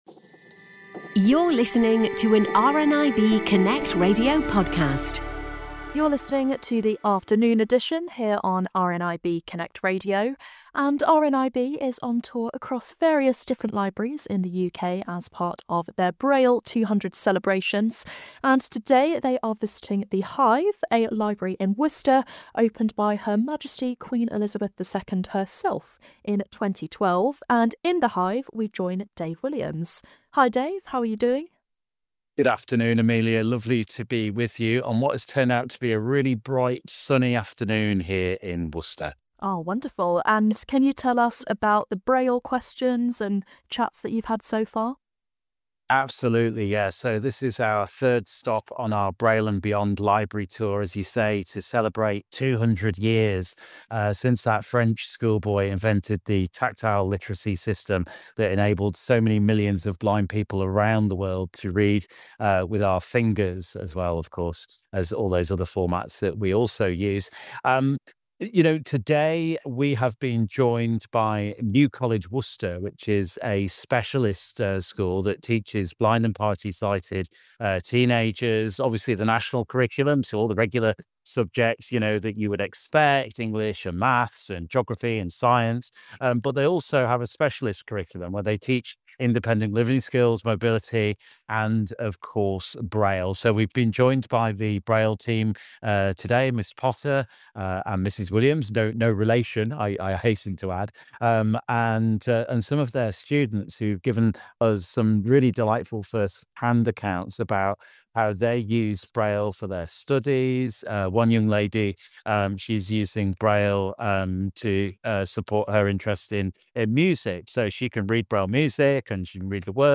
Braille and Beyond Tour Visits The Hive in Worcester - Afternoon Edition